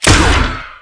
rocket2.wav